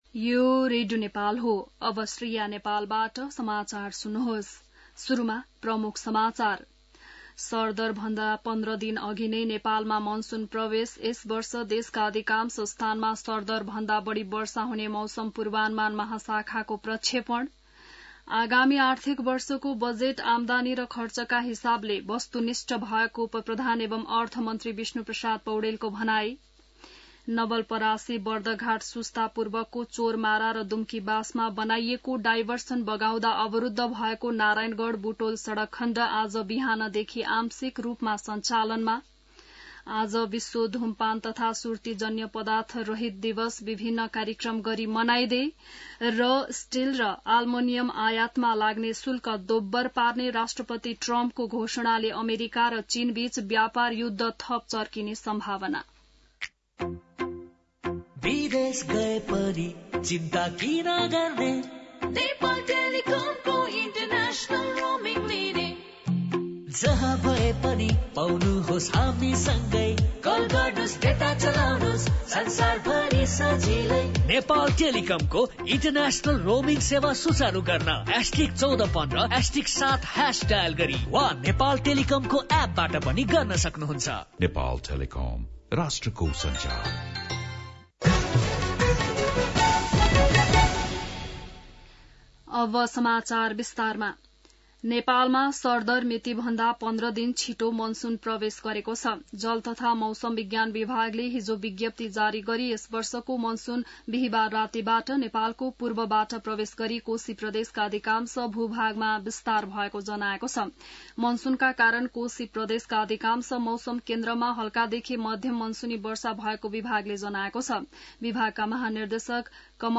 बिहान ७ बजेको नेपाली समाचार : १७ जेठ , २०८२